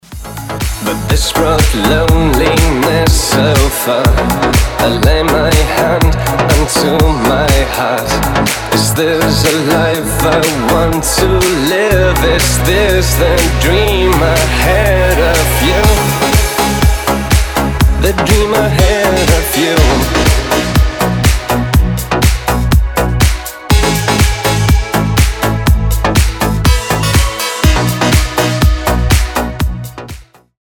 • Качество: 256, Stereo
deep house
dance
club
house